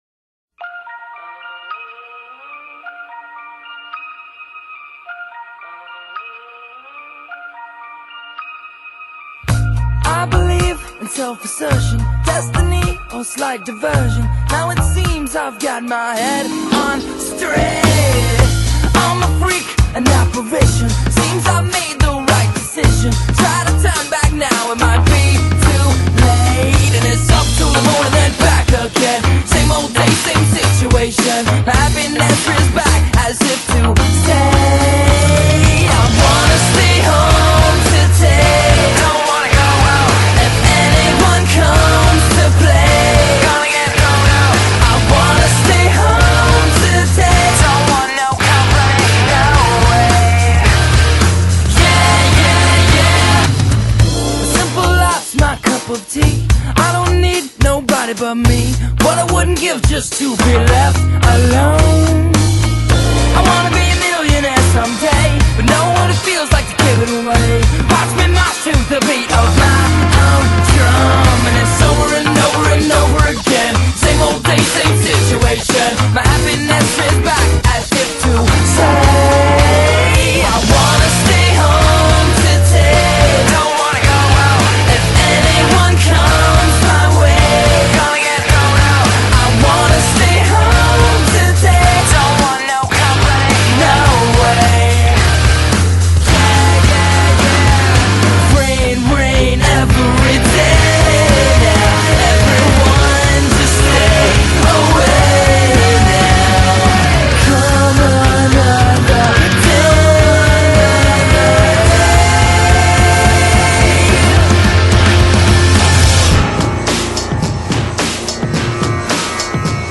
Soundtrack, Orchestral